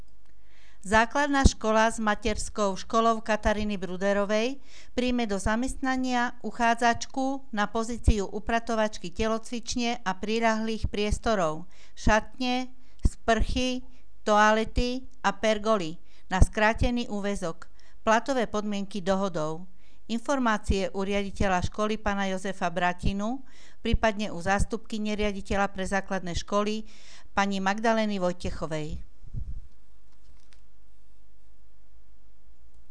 Hlásenie miestneho rozhlasu 12.10.2015